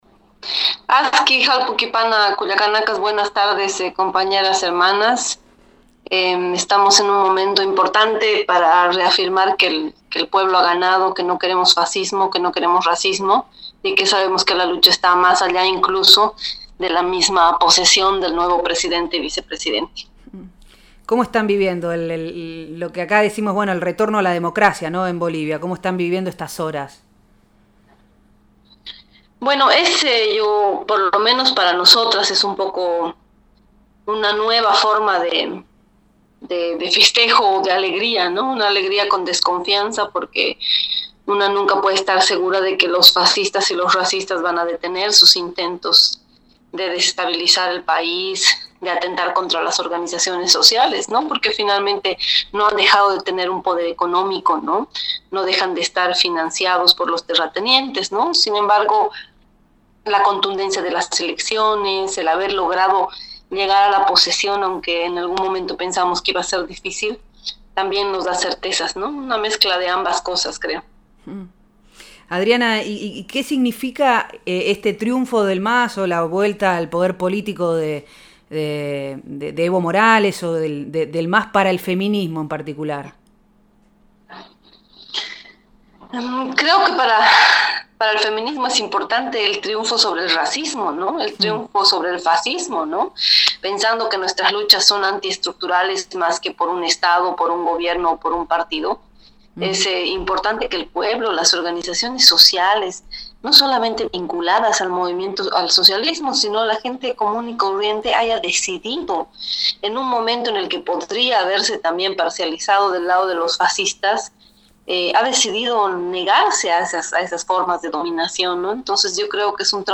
Nosotres les Otres, en vivo los lunes de 18 a 20 por FM Horizonte, 94.5